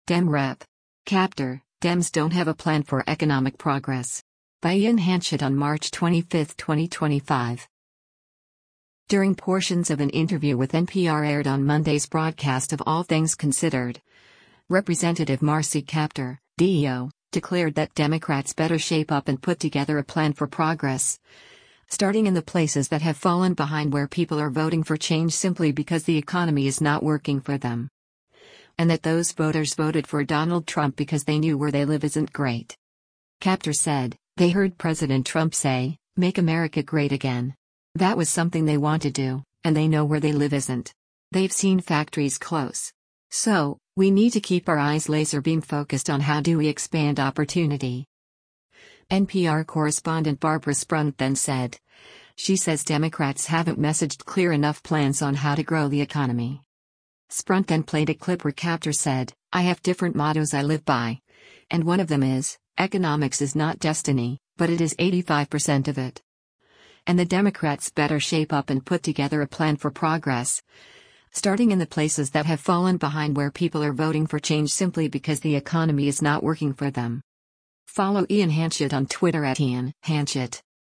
During portions of an interview with NPR aired on Monday’s broadcast of “All Things Considered,” Rep. Marcy Kaptur (D-OH) declared that Democrats “better shape up and put together a plan for progress, starting in the places that have fallen behind where people are voting for change simply because the economy is not working for them.” And that those voters voted for Donald Trump because they knew where they live isn’t great.